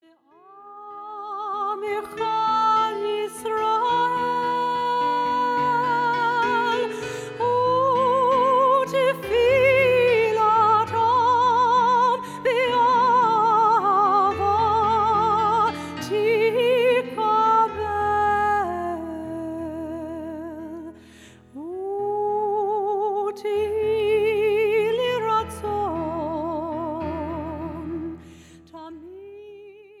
popular adult contemporary worship songs